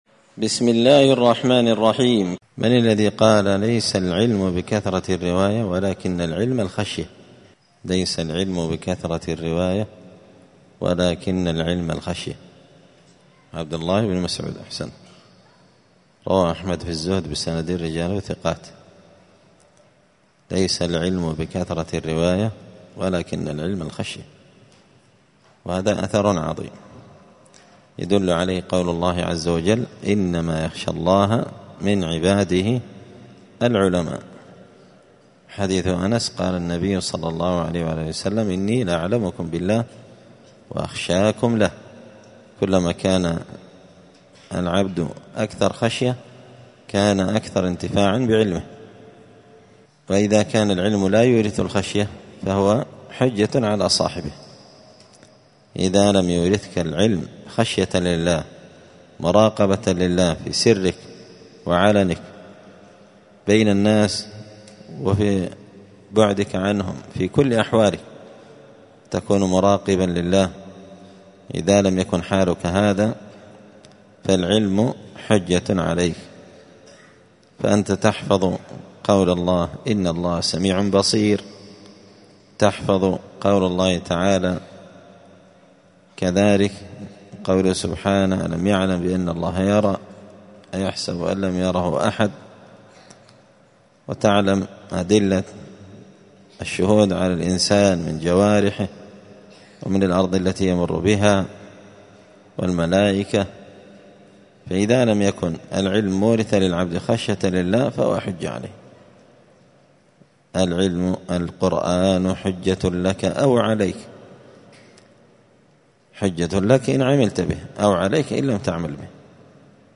دار الحديث السلفية بمسجد الفرقان بقشن المهرة اليمن
الأحد 4 جمادى الآخرة 1445 هــــ | الدروس، الفواكه الجنية من الآثار السلفية، دروس الآداب | شارك بتعليقك | 69 المشاهدات